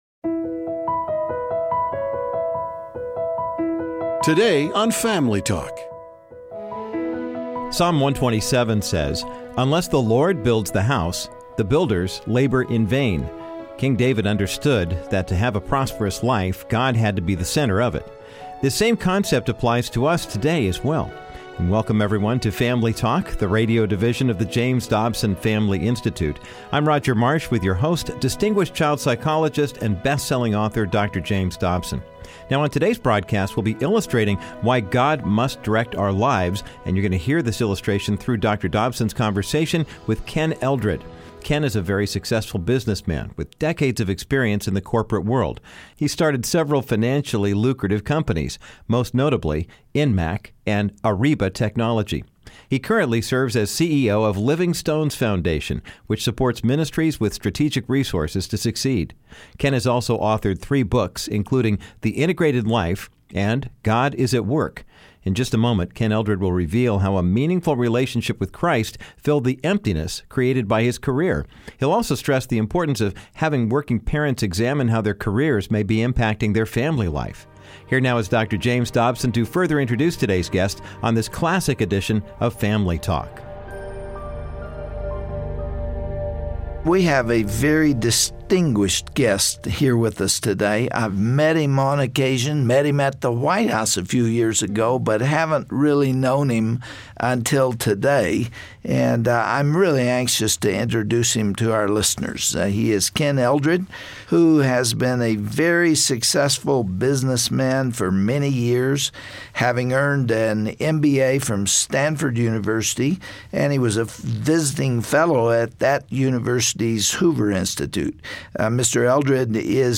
Psalm 127 says, Unless the Lord builds the house, the builders labor in vain. On this classic Family Talk broadcast, Dr. Dobson sits down author and business man